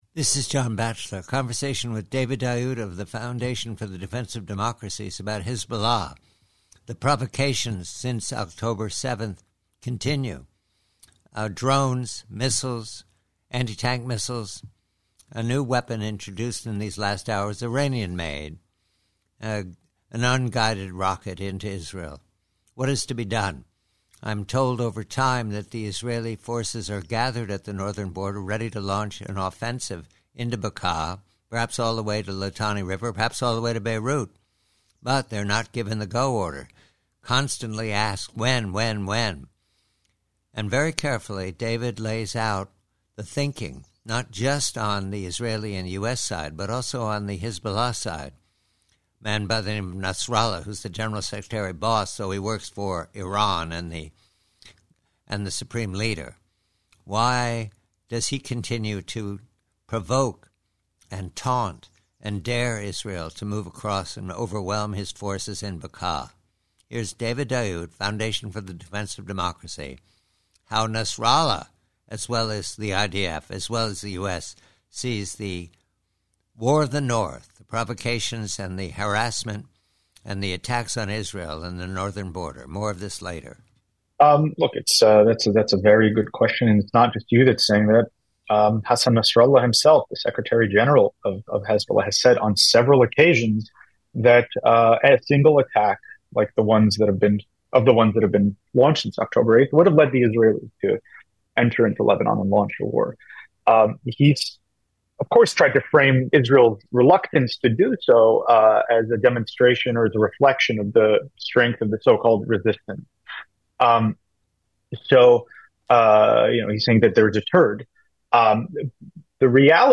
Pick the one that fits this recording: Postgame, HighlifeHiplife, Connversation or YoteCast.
Connversation